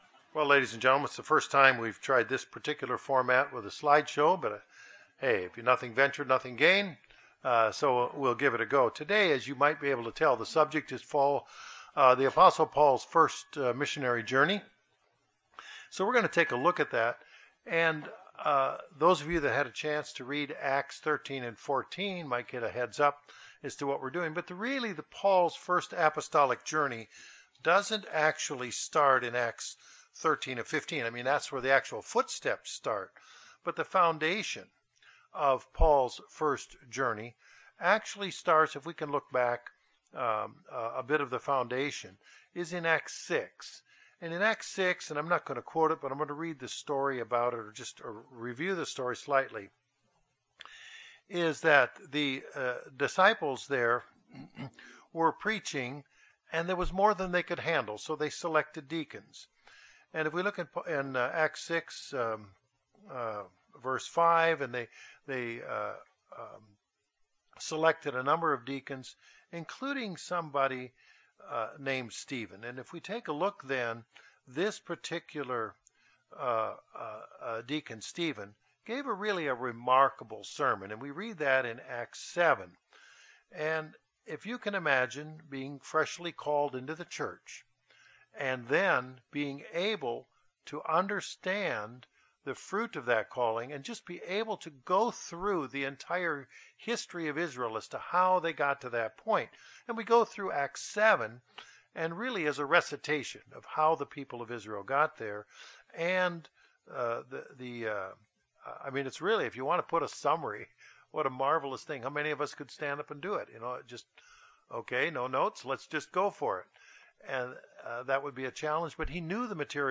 This Bible Study walks us through chapters 13 & 14 of the Book of Acts recounting Paul's first apostolic journey to share the Gospel.